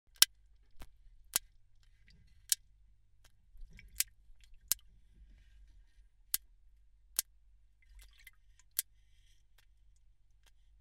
In a world first, researchers at our Leigh Marine Laboratory recorded sharks making sounds, upending the notion that all sharks are silent.
It's likely the noises come from snapping flattened teeth.